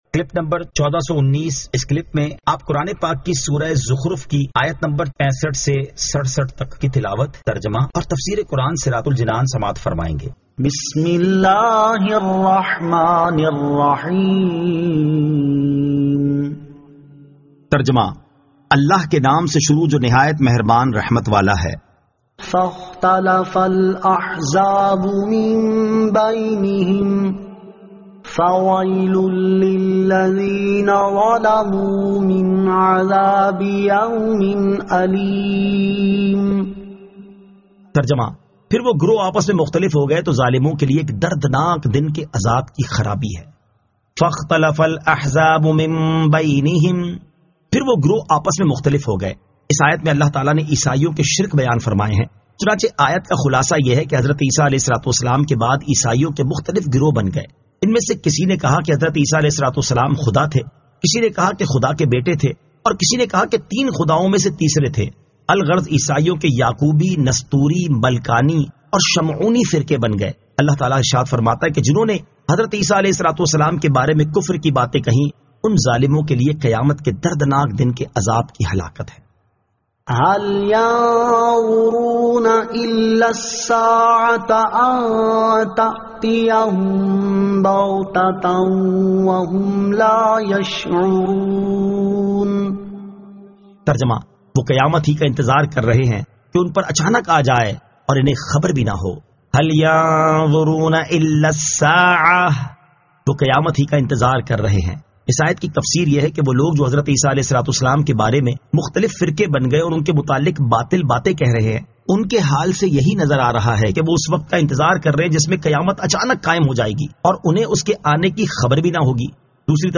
Surah Az-Zukhruf 65 To 67 Tilawat , Tarjama , Tafseer